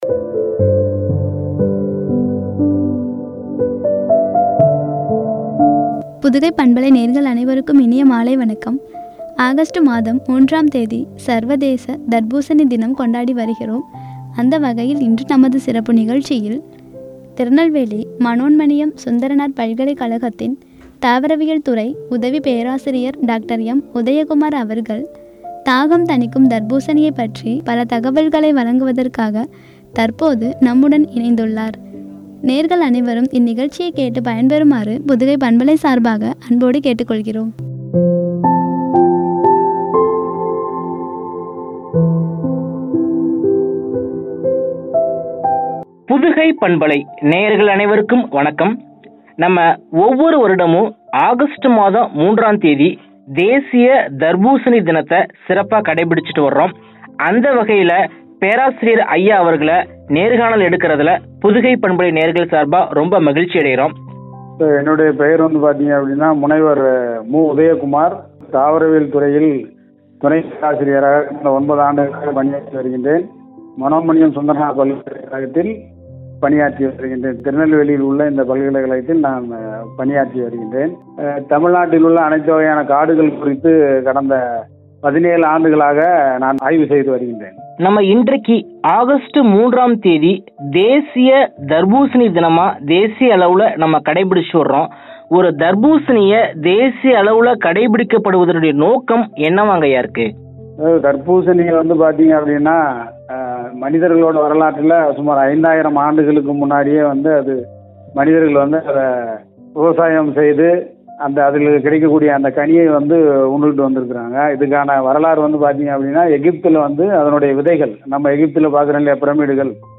வழங்கிய உரையாடல்.